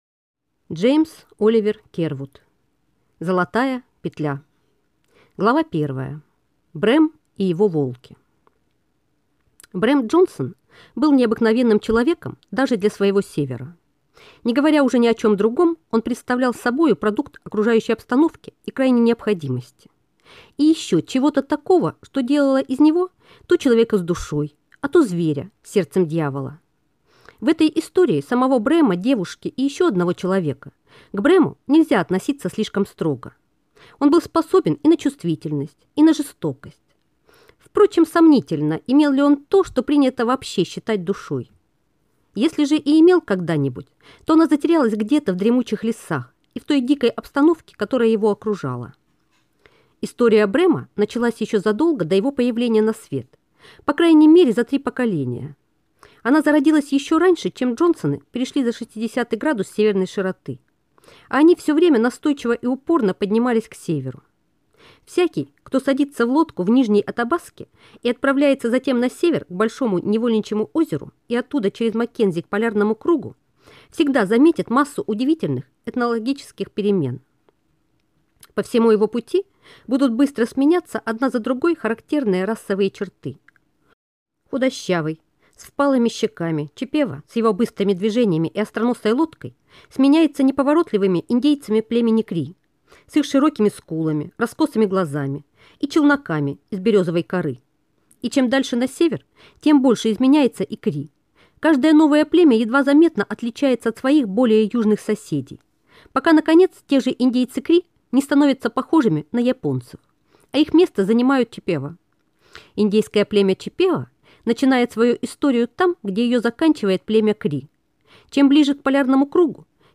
Аудиокнига Золотая петля | Библиотека аудиокниг